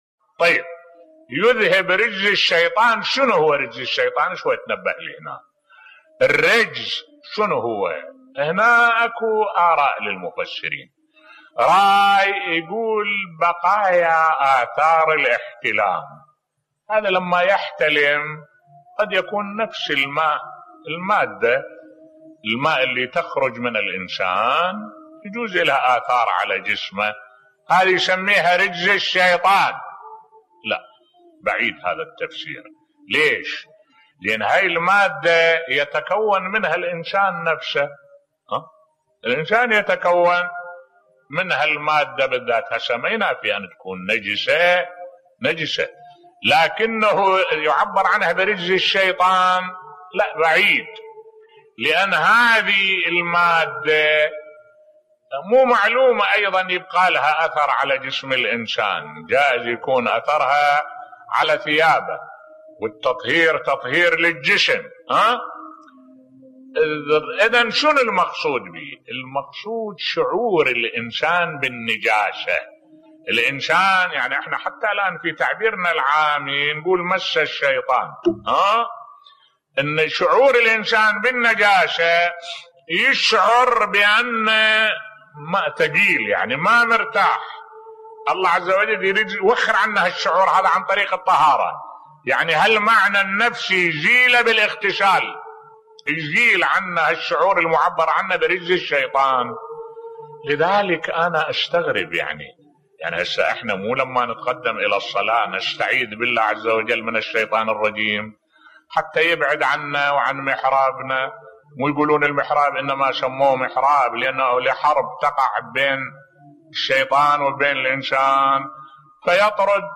ملف صوتی قال تعالى: ويذهب عنكم رجز الشيطان بصوت الشيخ الدكتور أحمد الوائلي